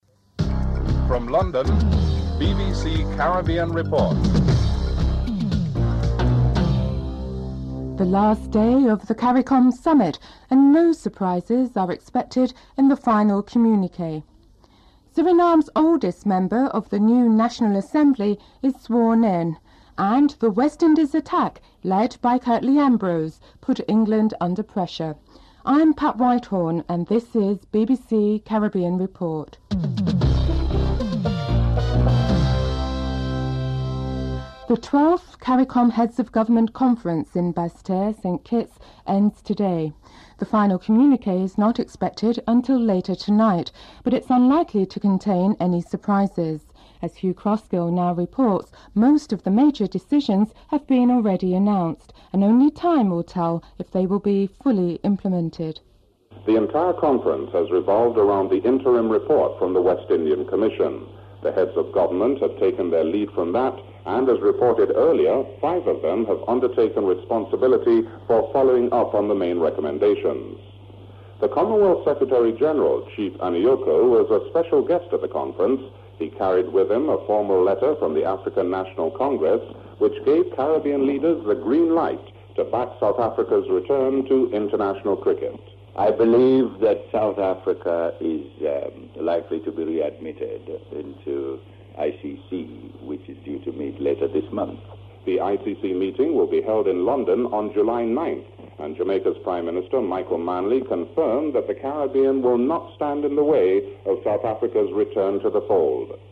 Interview with Peter Penfold (11:39-13:23)
6. Jonathan Agnew reports that the West Indies finishes the first day of the test match against England in a strong position with England at 269 for 8 wickets (13:24-14:54)